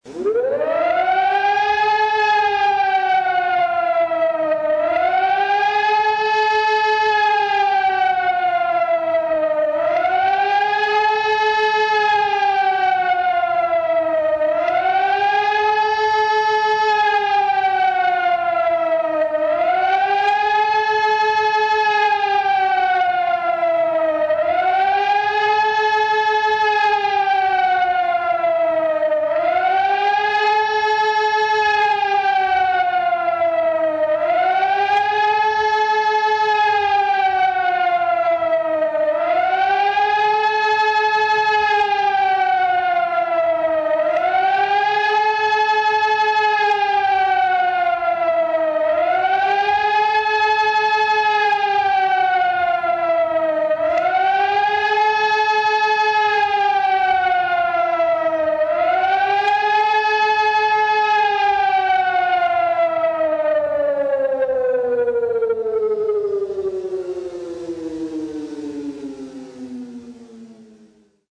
si-verhalten_heulton.mp3